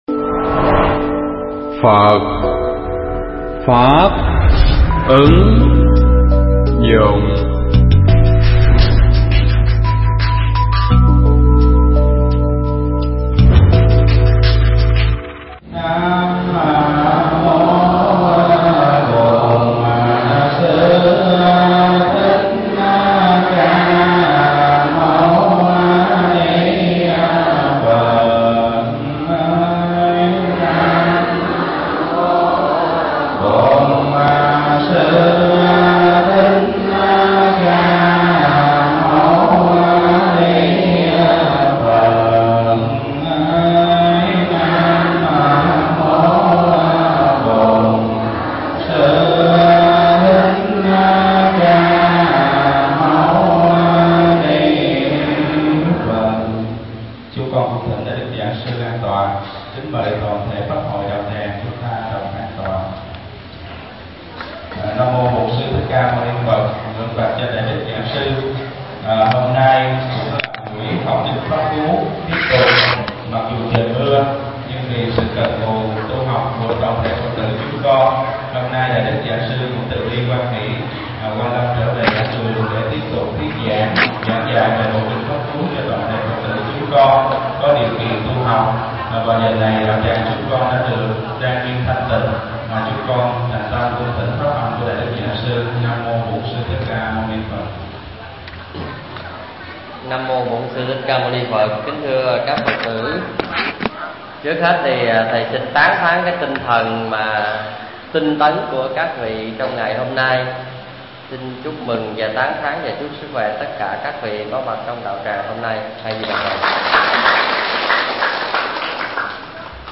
Tải mp3 Pháp thoại Kinh Pháp Cú Phẩm Song Yếu (Câu 15 đến 20)